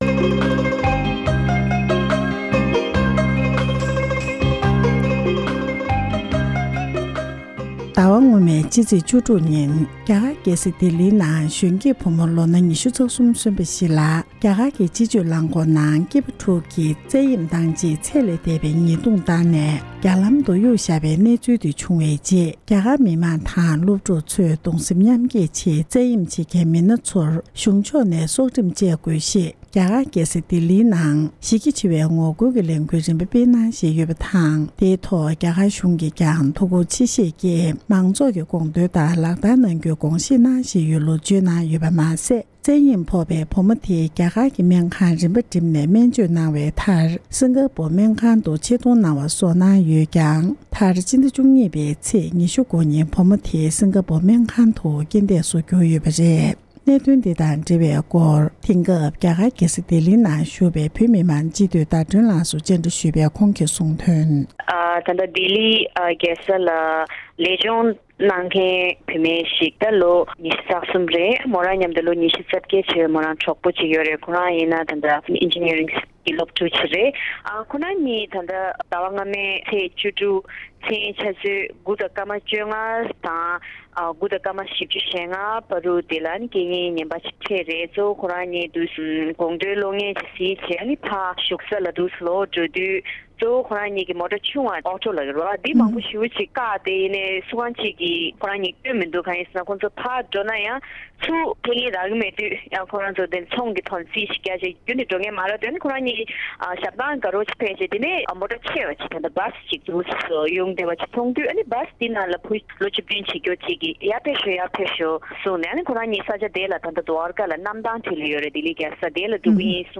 འབྲེལ་ཡོད་མི་སྣར་ཞིབ་ཕྲའི་གནས་ཚུལ་བཀའ་འདྲི་ཞུས་པར་གསན་རོགས་ཞུ༎